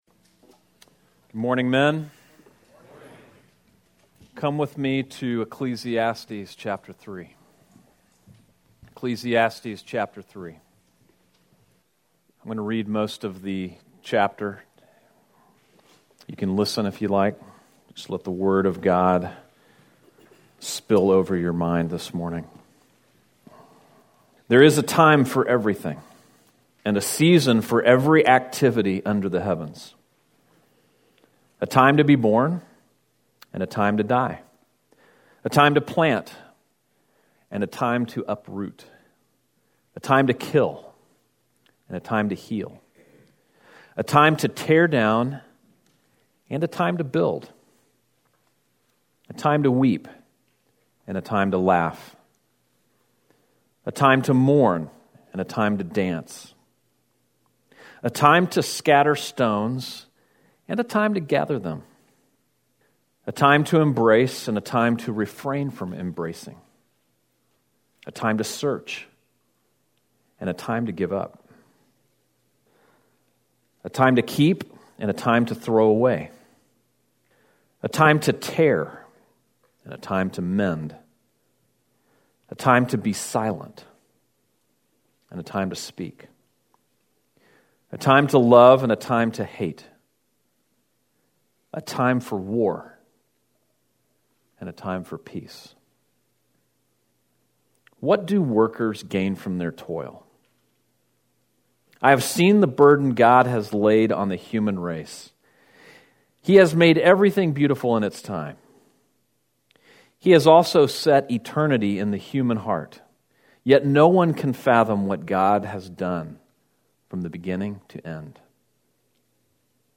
Men's Retreat Session 2, Frontier Camp 2018 | Sermon | Grace Bible Church
Men's Retreat Session 2, Frontier Camp 2018